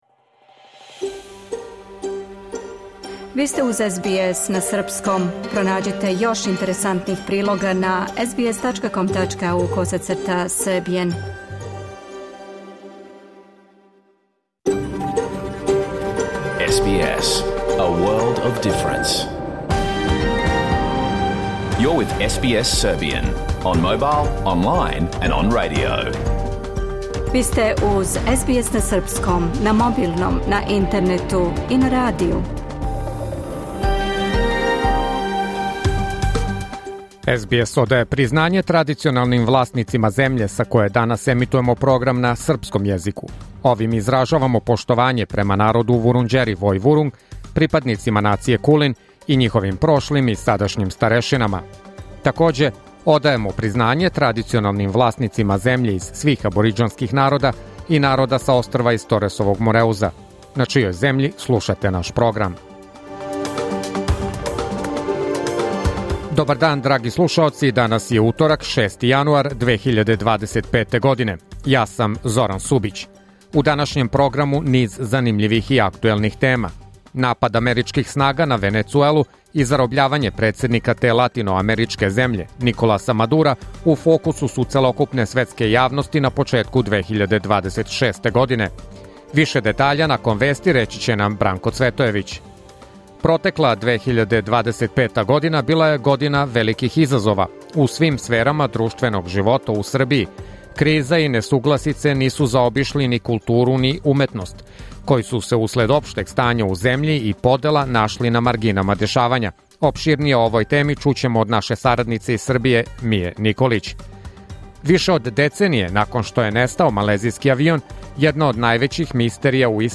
Програм емитован уживо 6. јануара 2026. године